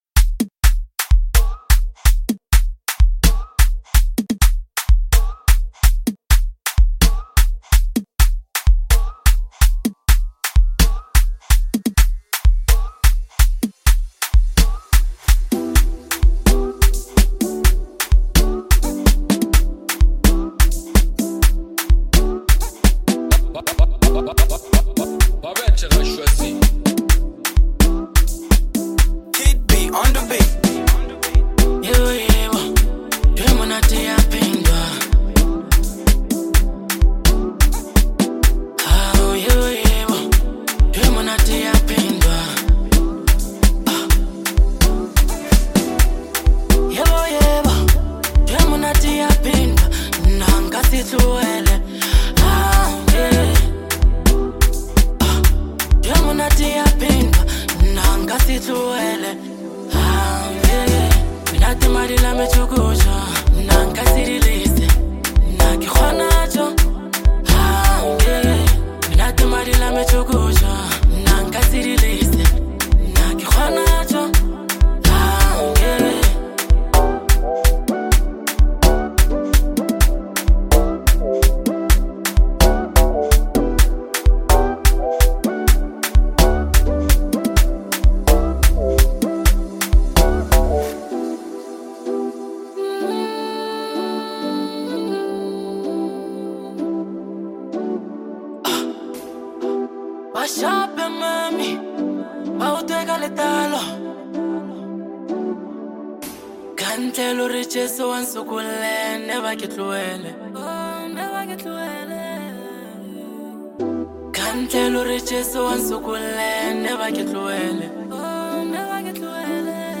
powerful and emotionally charged song
lekompo